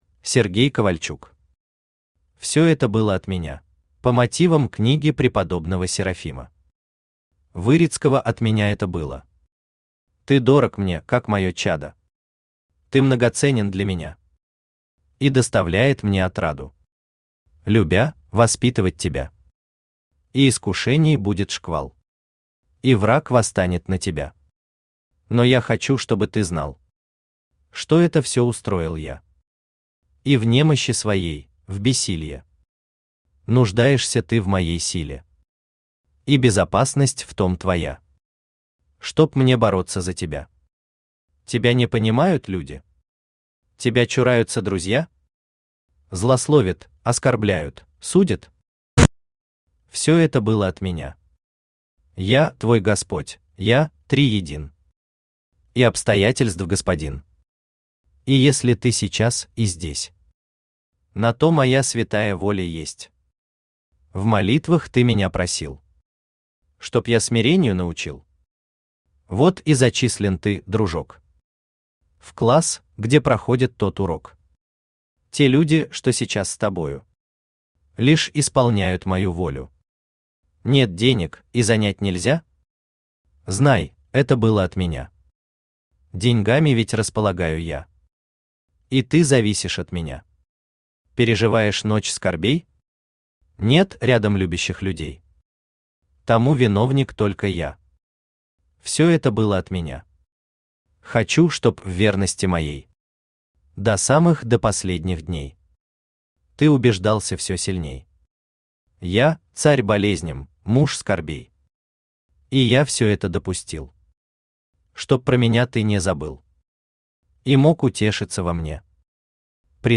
Аудиокнига Все это было от Меня!
Читает аудиокнигу Авточтец ЛитРес.